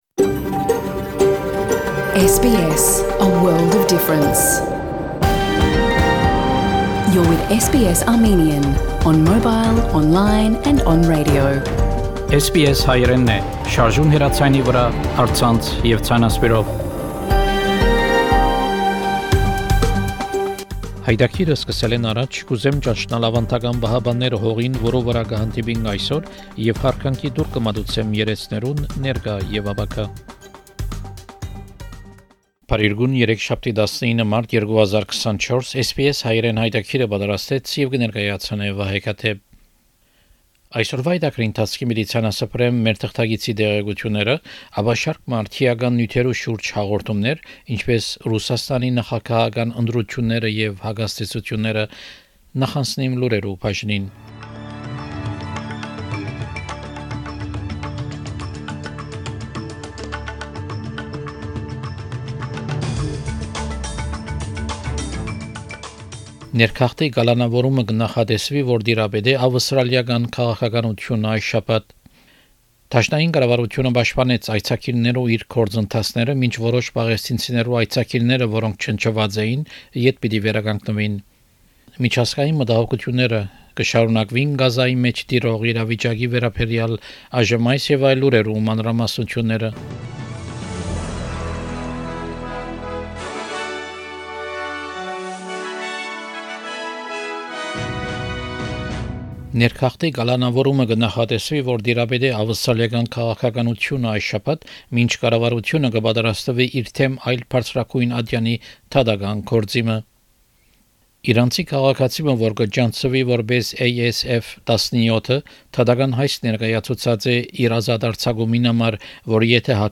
SBS Armenian news bulletin – 19 March 2024